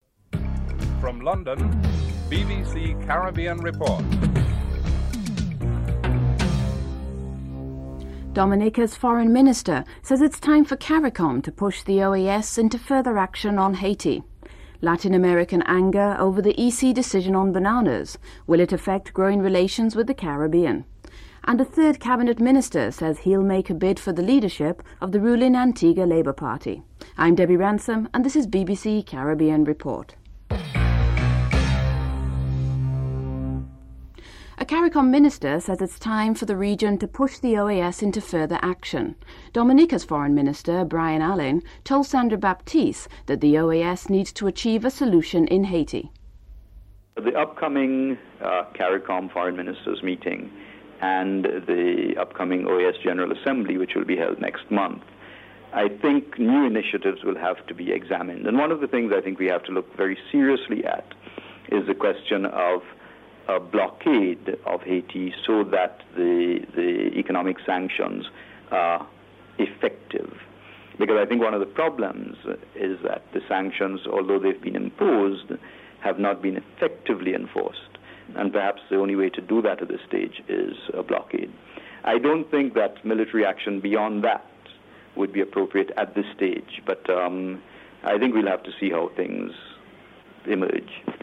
Headlines (00:00-0031)